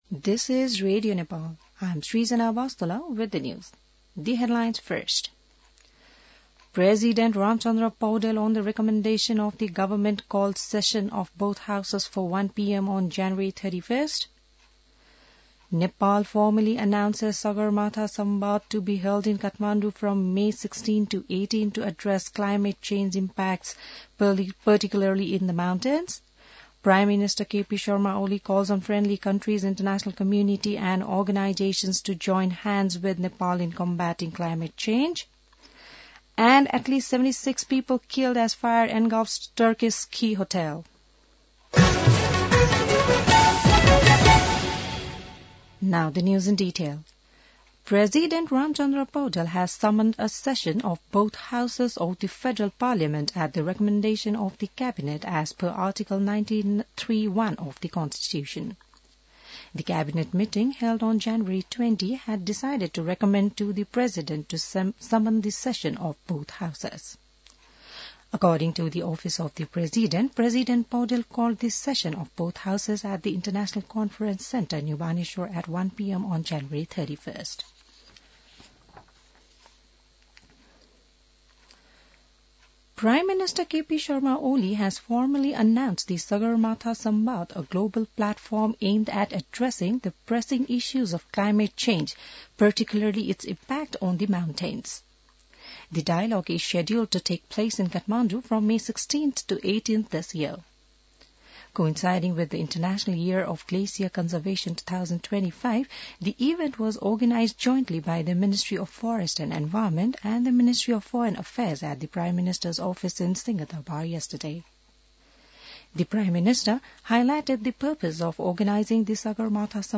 बिहान ८ बजेको अङ्ग्रेजी समाचार : १० माघ , २०८१